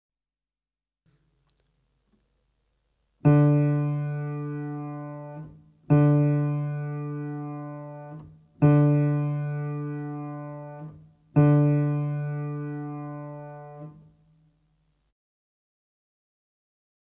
58 Tuning Note - D-String (Cello)